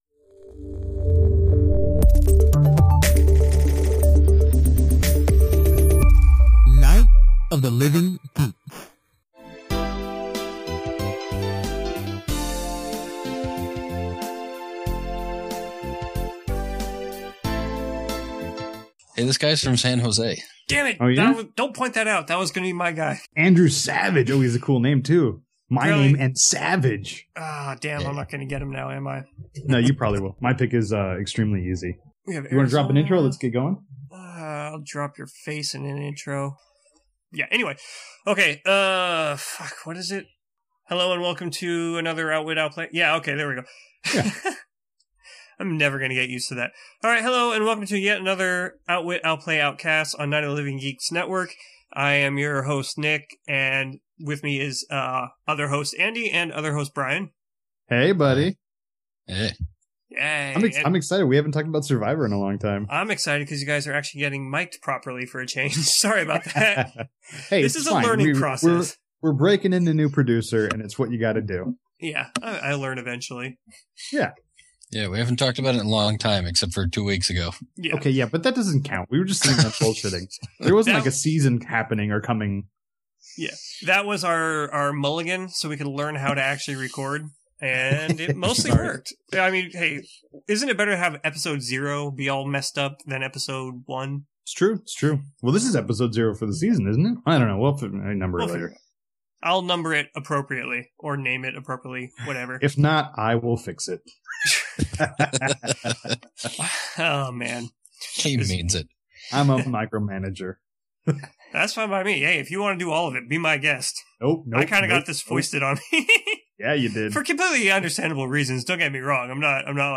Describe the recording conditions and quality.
From the audio-ashes of last week’s… learning experience …we properly produce a real episode that isn’t recorded through 8-year old speakers!